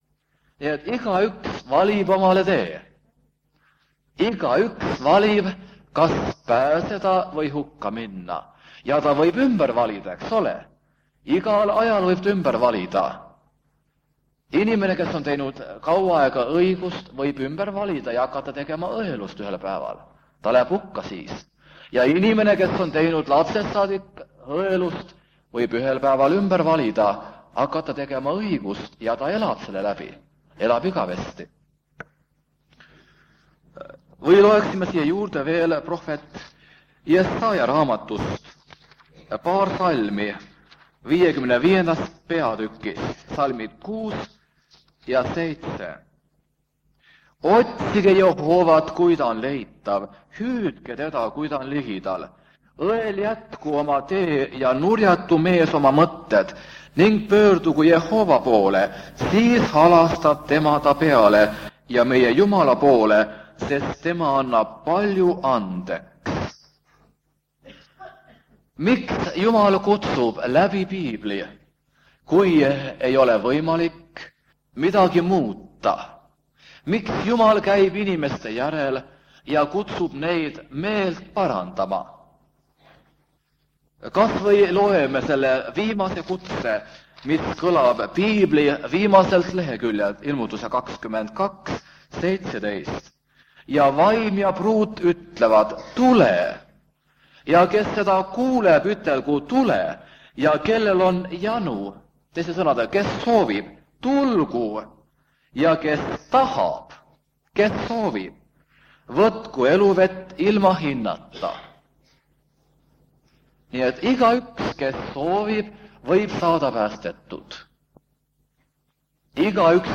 Koosolek vanalt lintmaki lindilt.